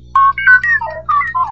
File File history File usage R5-D4_ANH.ogg (file size: 22 KB, MIME type: application/ogg ) Summary File:R5-D4 ANH.ogg Information Description The sound of the droid R5-D4 shortly before his motivator blew out. Source Star Wars : Episode IV A New Hope Licensing This is an Ogg Vorbis sound sample.